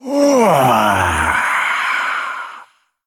行为语音下载